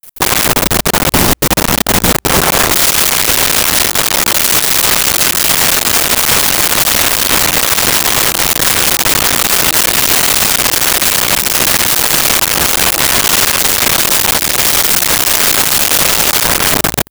Fly Buzz 01
Fly Buzz 01.wav